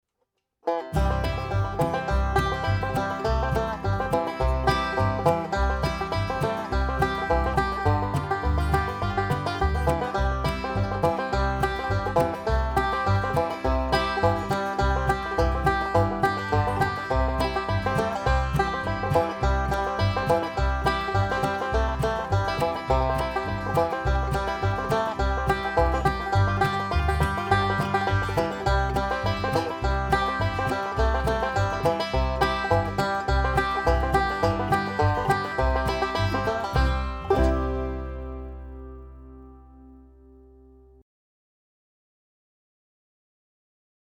5-STRING BANJO SOLO Three-finger "Scruggs style"
DIGITAL SHEET MUSIC - 5-STRING BANJO SOLO
learning speed and performing speed